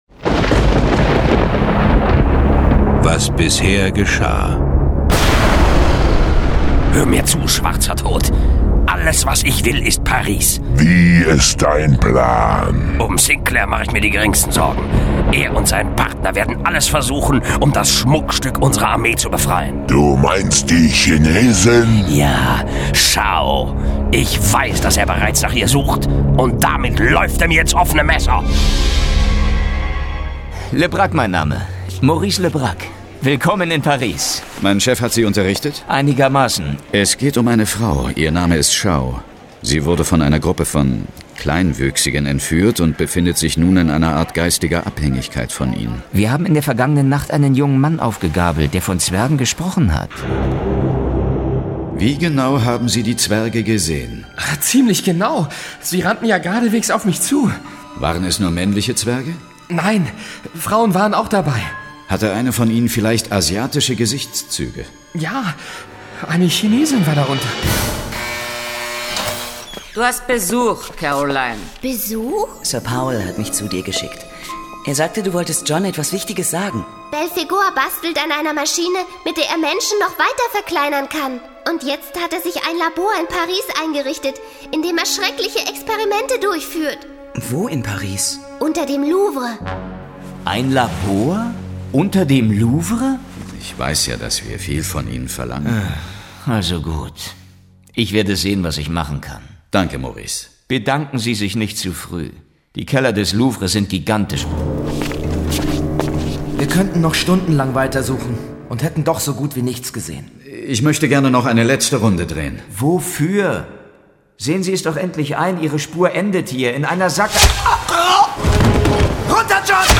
John Sinclair - Folge 13 Gefangen in der Mikrowelt. Hörspiel.